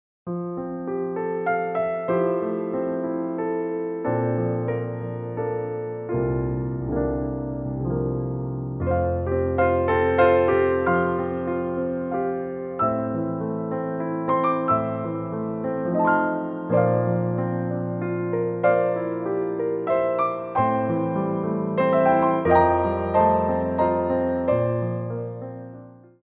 for Ballet Class
Pliés
6/8 (16x8)